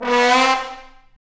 Index of /90_sSampleCDs/Roland L-CDX-03 Disk 2/BRS_Bone Sec.FX/BRS_Bone Sec.FX